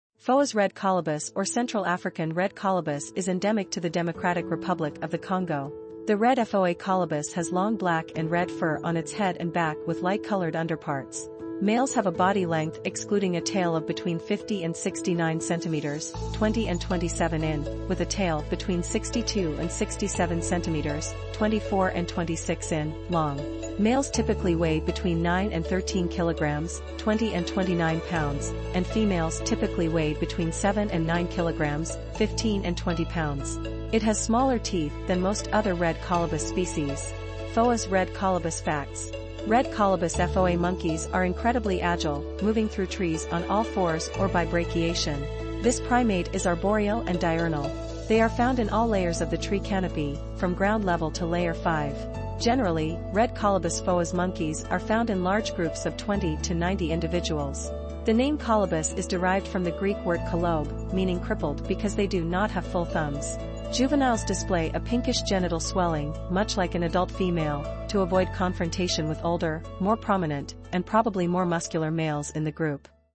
Foas-Red-Colobus.mp3